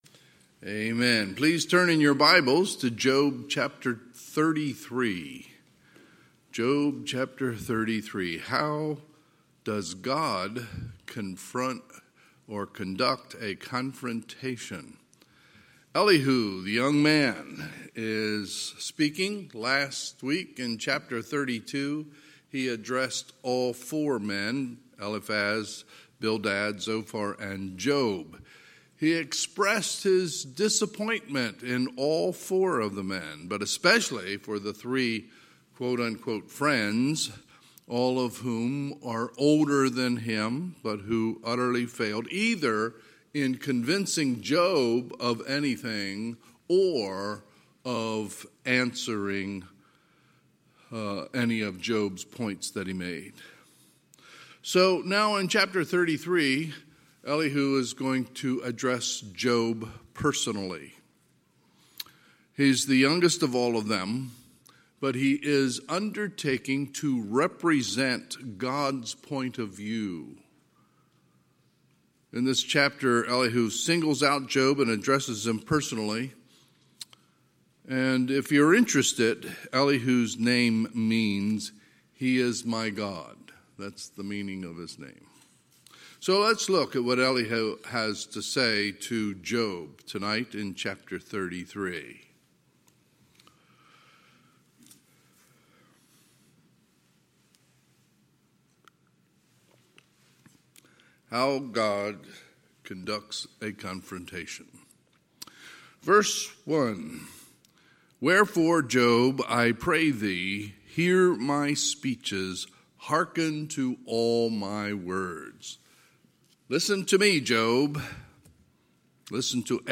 Sunday, June 27, 2021 – Sunday PM
Sermons